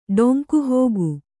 ♪ ḍoŋku